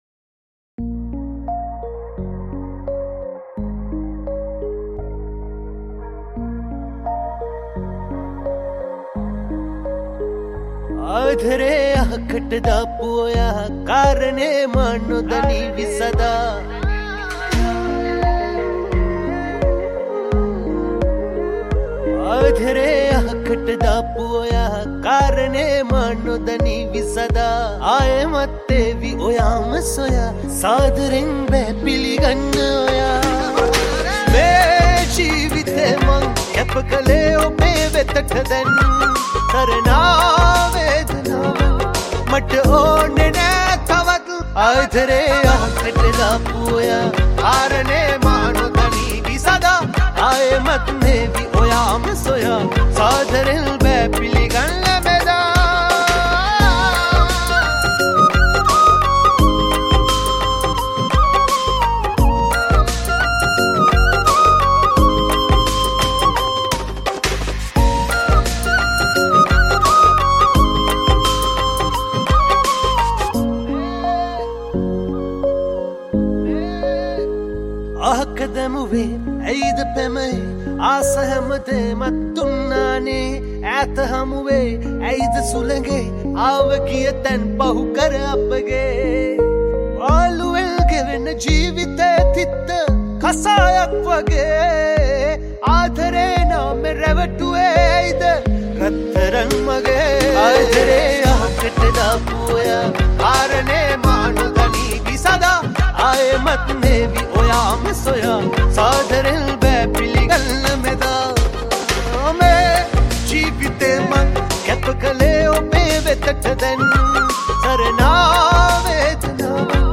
High quality Sri Lankan remix MP3 (3.1).